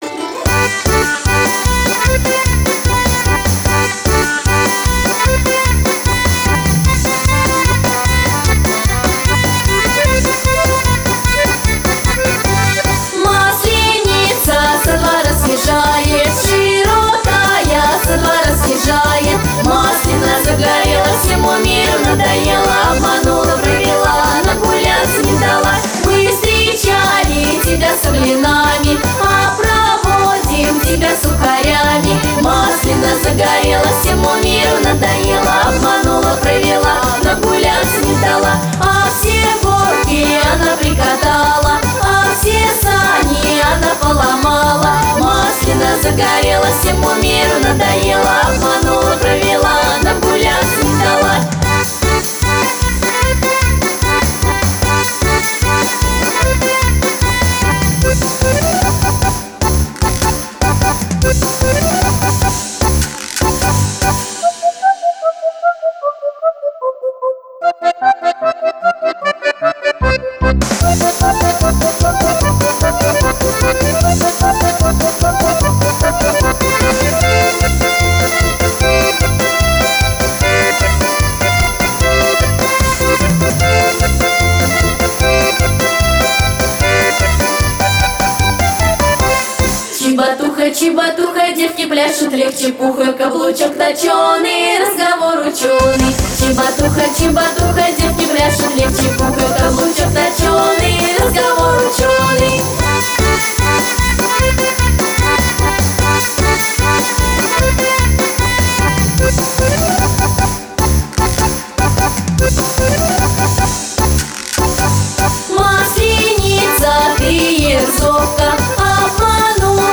• Жанр: Детские песни
теги: масленица, праздник, народный мотив, минус